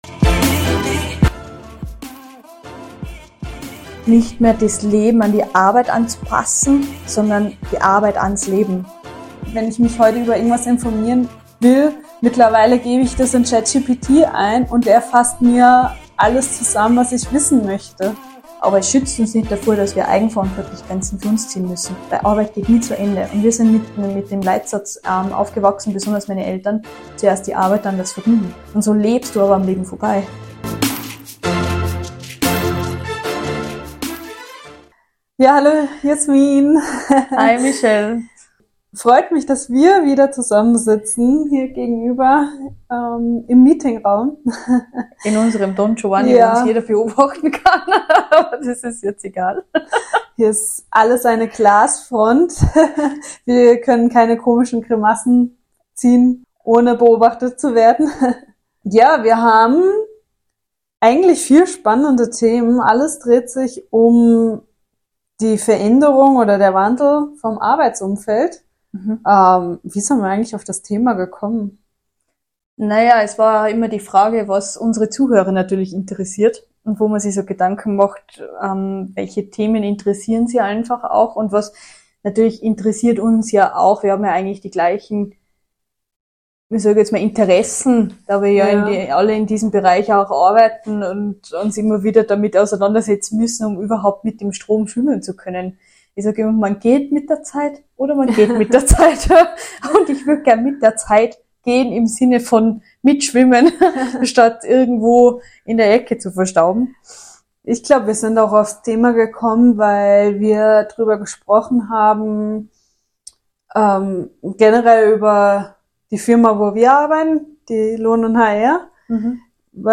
Gespräch über den Wandel der Arbeitswelt durch Technologie, Digitalisierung und neue Trends im Bewerbermanagement.